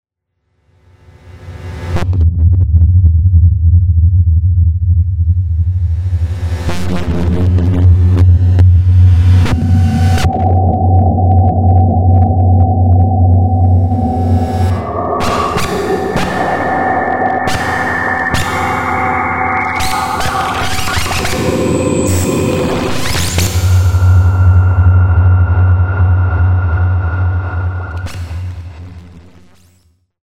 ____ SynC modular edited Nord Lead.mp3